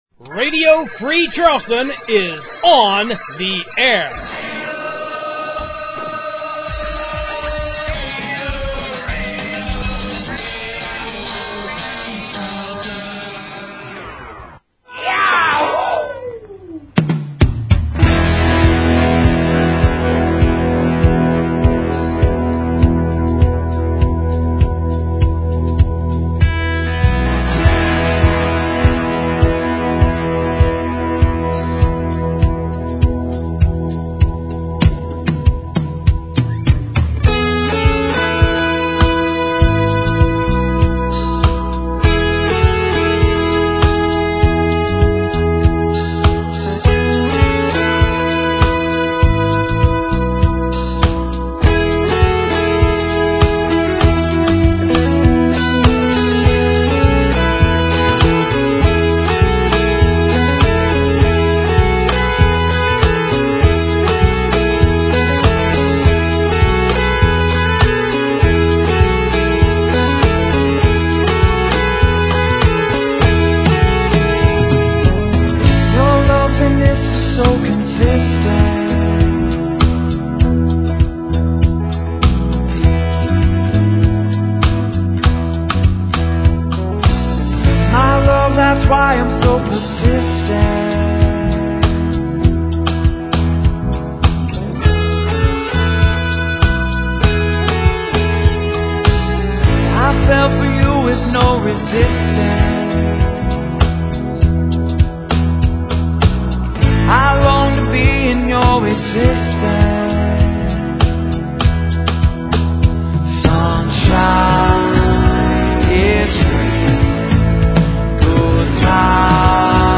I ad-libbed the announcing with no script this week (as usual) and didn’t realize that it’s a two-day event until I started writing these notes.